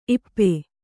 ♪ ippe